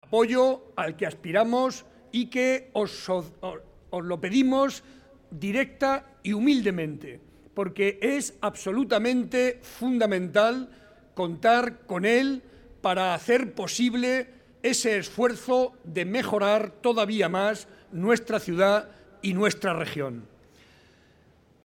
Audio Barreda mitin Guadalajara 1